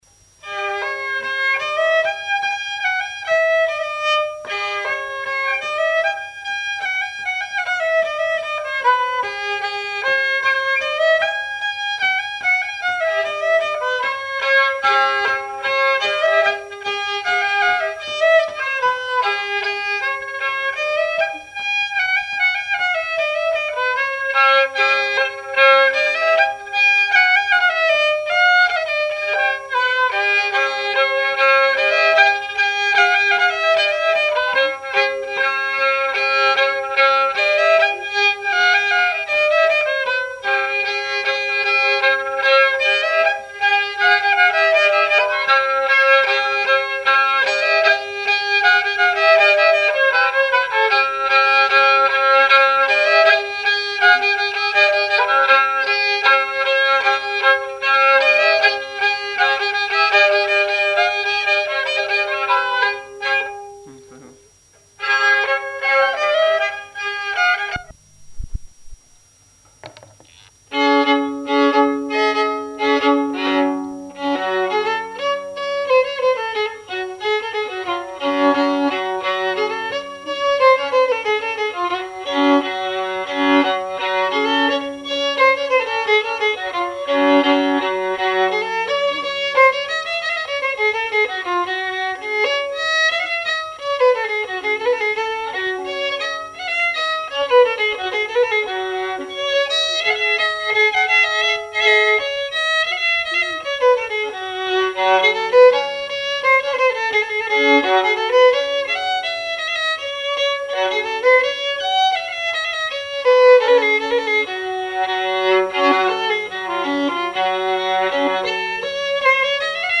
Airs scandinaves
répertoire folk
Saint-Julien-des-Landes
Pièce musicale inédite